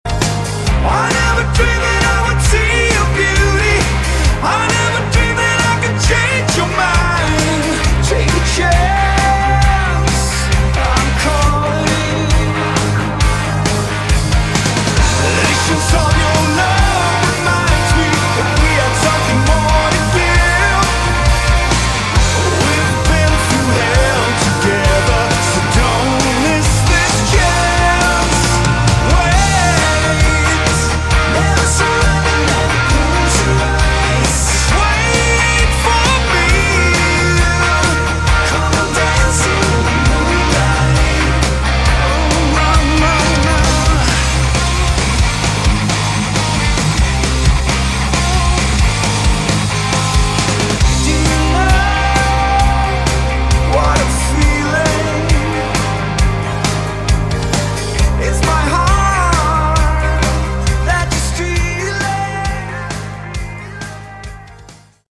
Category: Hard Rock
lead vocals
guitars and vocals
bass and vocals
drums, percussion, and vocals
Harmonies,hooks, & catchy songs/lyrics with minimum filler.